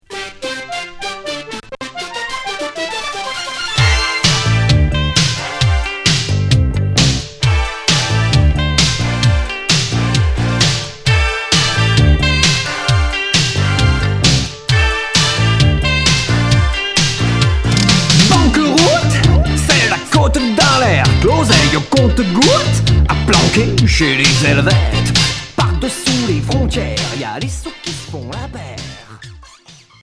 Rock new-wave Deuxième 45t retour à l'accueil